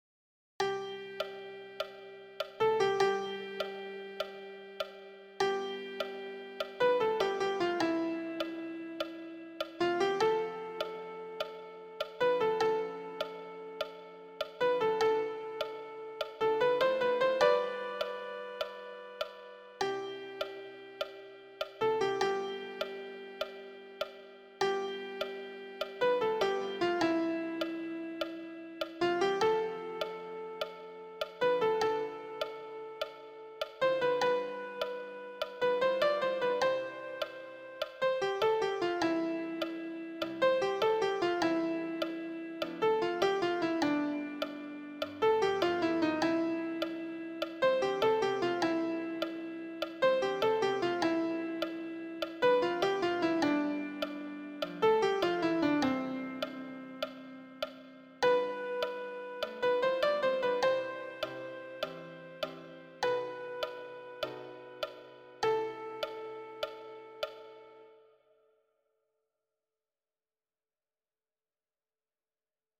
Répétition de la pièce musicale
Répétition SATB par voix
Soprano
Non, je ne regrette rien_soprano-mix.mp3